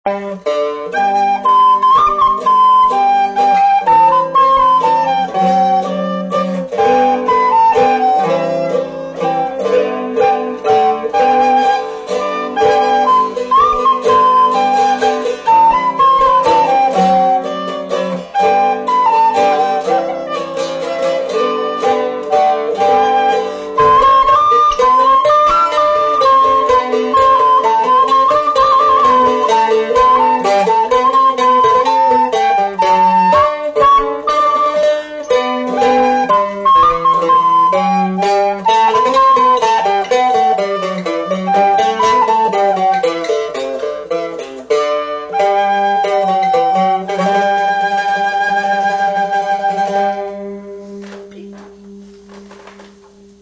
Octave Mandolin
Soprano Recorder